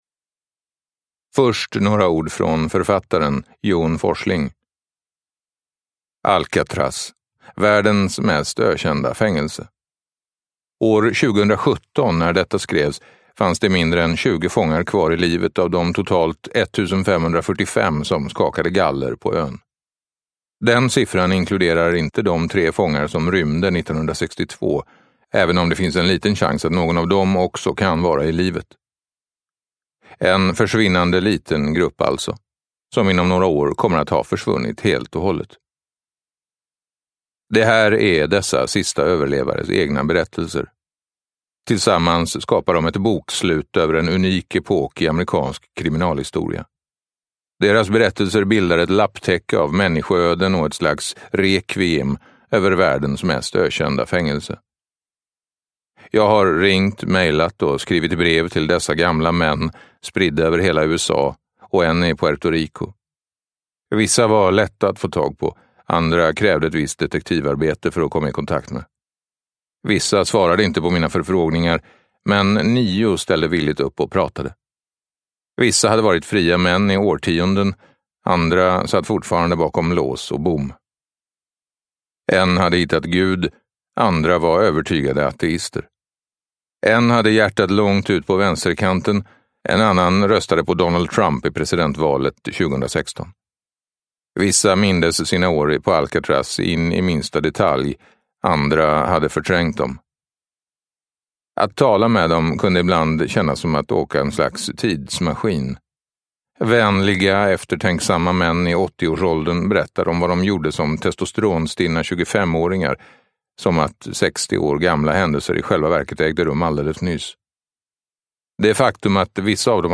Alcatraz - de sista fångarnas berättelser från ett av världens mest ökända fängelser – Ljudbok – Laddas ner